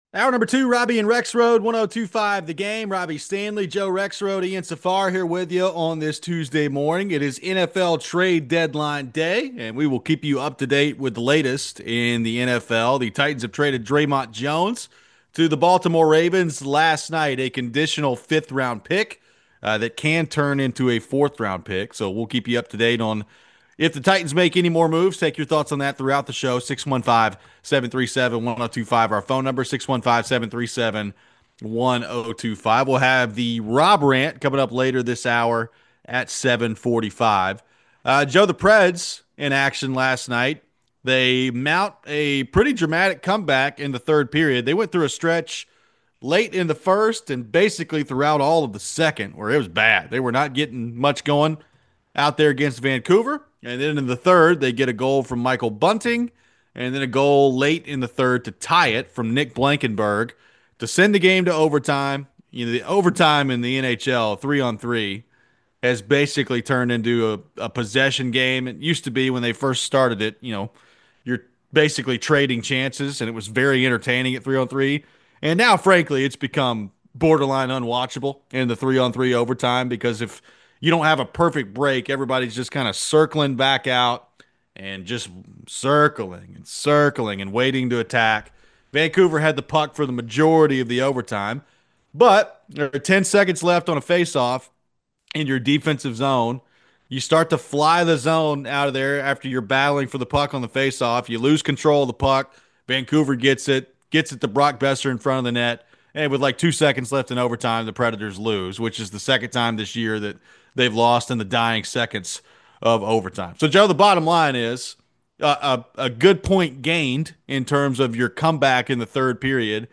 We take some phones.